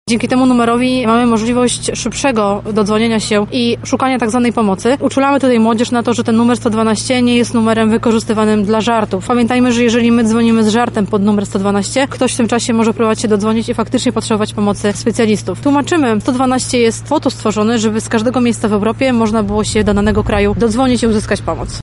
W tym roku obchody były szczególne, bo odbywały się w nowej siedzibie Centrum Powiadamiania Ratunkowego w Lublinie. Dziś doceniono osoby po drugiej stronie słuchawki, czyli operatorów numerów alarmowych.